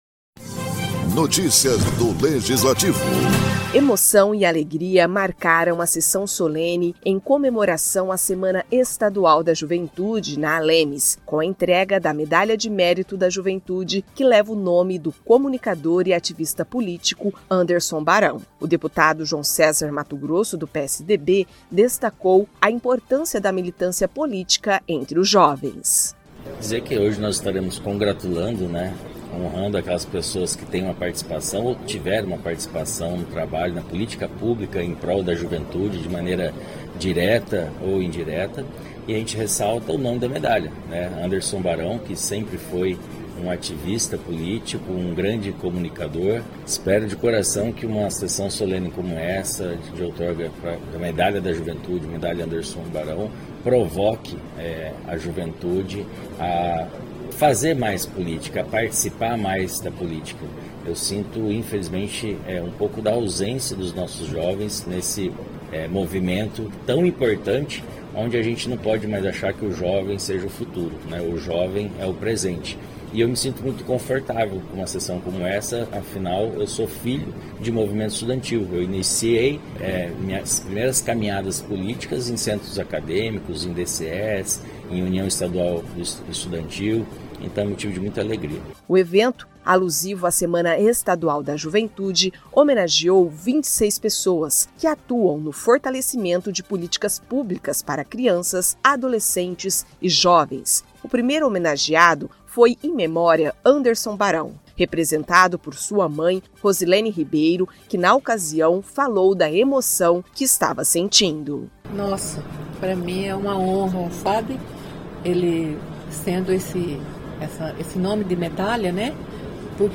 Emoção e alegria marcaram a sessão solene em comemoração à Semana Estadual da Juventude, na Assembleia Legislativa de Mato Grosso do Sul (ALEMS), com a entrega da Medalha de Mérito da Juventude que leva o nome do comunicador e ativista político Anderson Barão.